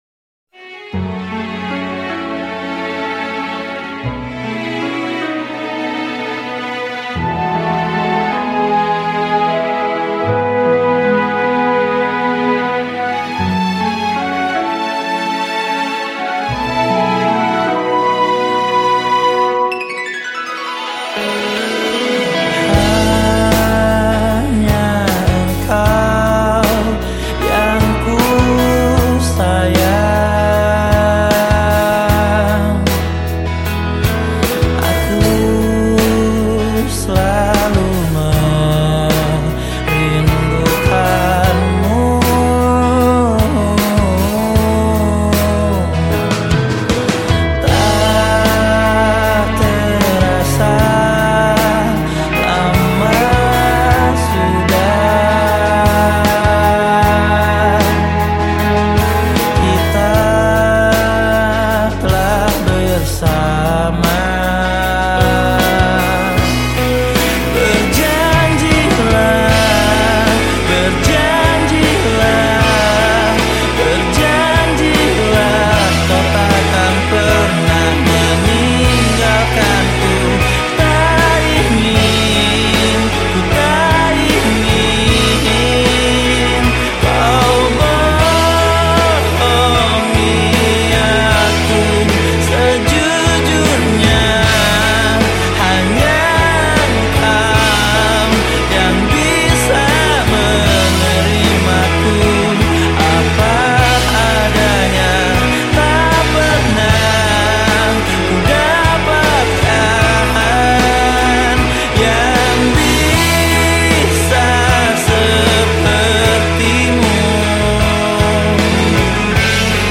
Genre Musik                     : Rock Alternatif